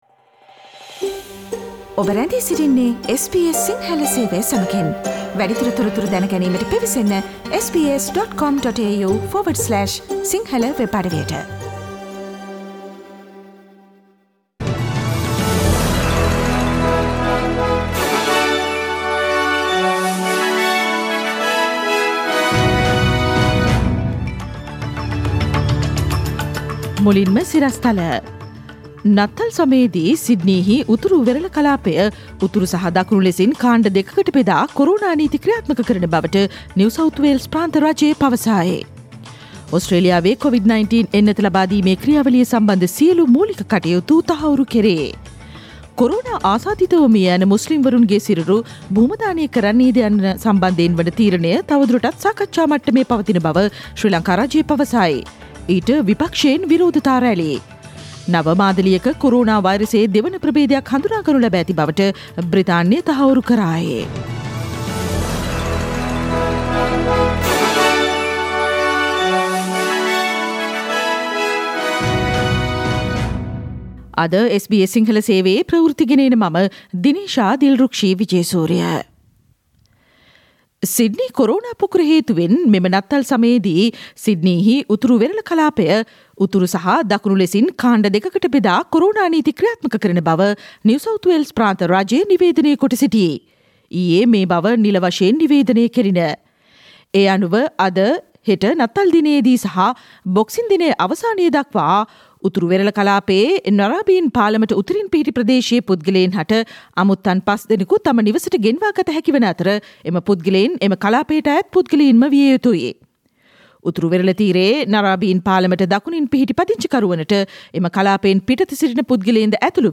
Daily News bulletin of SBS Sinhala Service: Thursday 24 December 2020
Today’s news bulletin of SBS Sinhala radio – Thursday 24 December 2020.